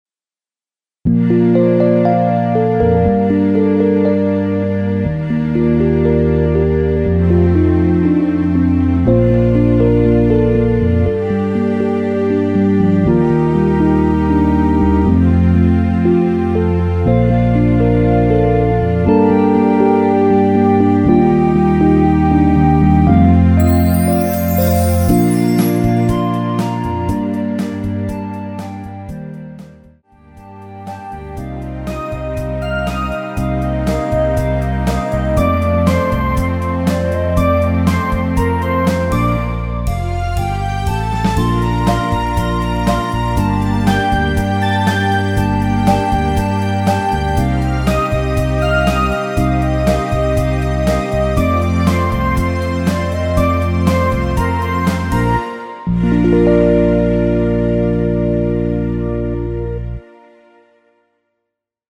엔딩이 페이드 아웃이라 라이브 하시기 좋게 엔딩을 만들어 놓았습니다.(미리듣기 참조)
Bb
멜로디 MR이란
앞부분30초, 뒷부분30초씩 편집해서 올려 드리고 있습니다.